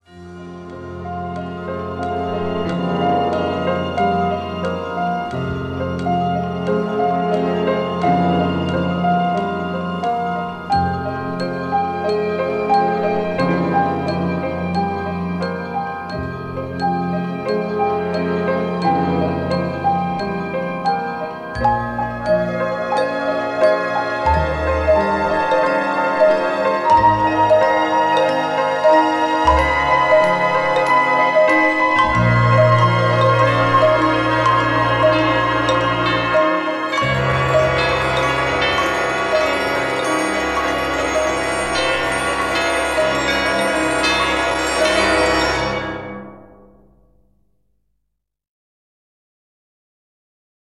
• Качество: 128, Stereo
грустные
саундтреки
без слов
инструментальные
тревожные
печальные